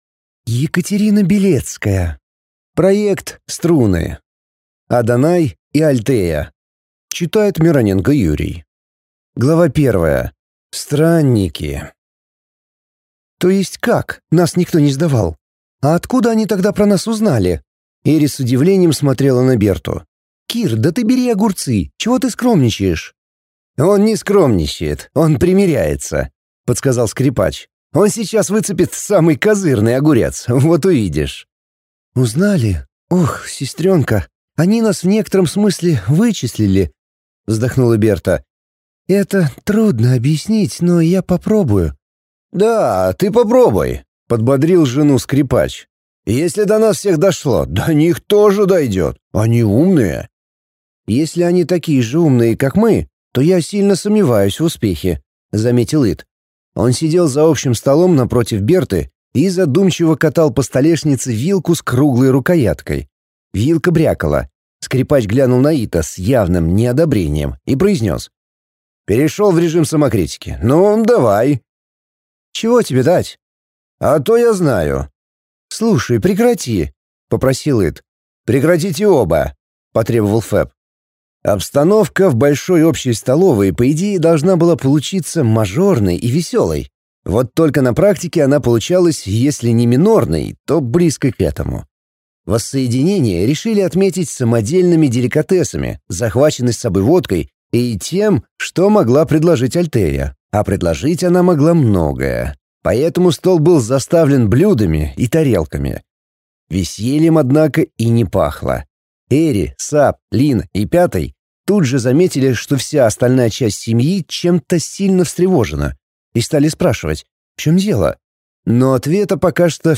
Аудиокнига Адонай и Альтея | Библиотека аудиокниг